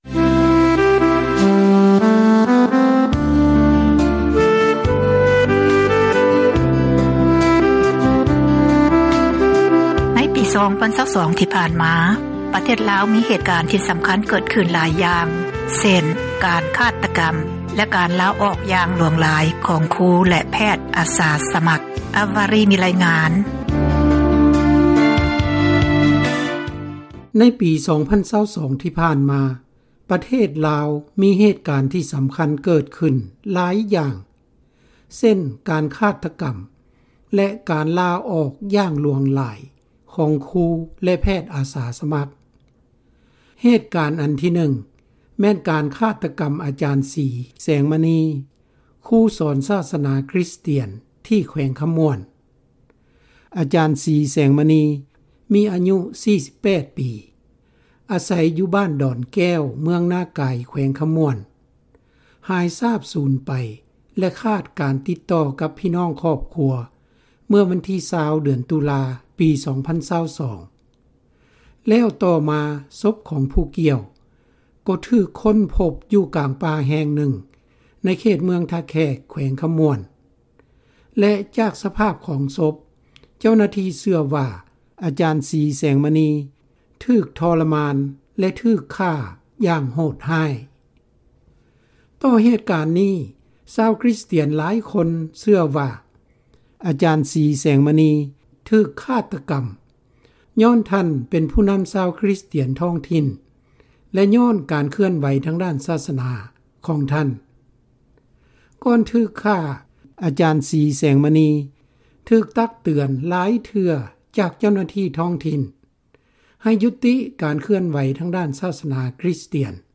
ດັ່ງຊາວຄຣິສຕຽນລາວຜູ້ນຶ່ງ ທີ່ຮູ້ເຫດການ ໄດ້ເລົ່າສູ່ວິທະຍຸ ເອເຊັຽ ເສຣີ ຟັງເມື່ອເດືອນພຶສຈິກາ ທີ່ຜ່ານມາວ່າ:
ຊາວລາວຄົນນຶ່ງ ກໍສເນີໃຫ້ເຈົ້າໜ້າທີ່ ເປີດເຜີຍຂໍ້ມູນ ຫຼື ຄວາມຂືບໜ້າ ຂອງການດຳເນີນຄະດີ ໃຫ້ປະຊາຊົນຊາບ.